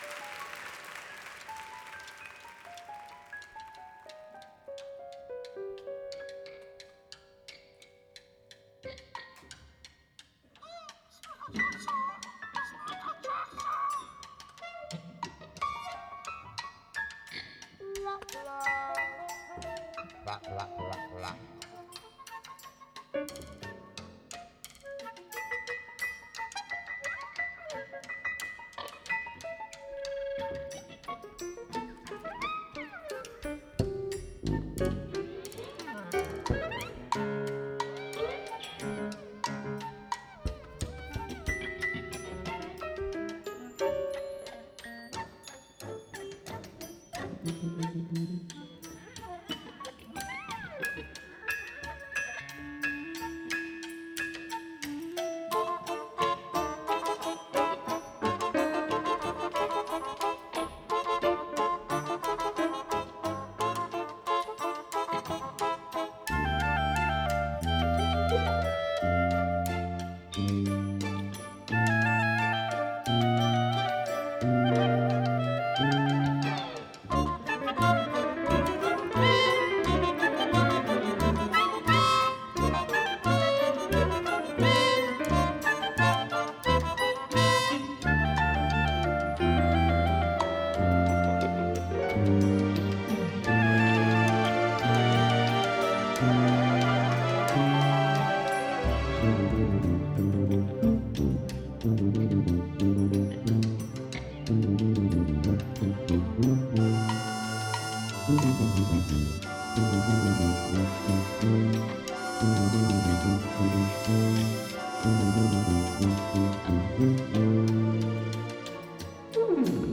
Тип альбома: Студийный
Жанр: Post-Bop
trumpet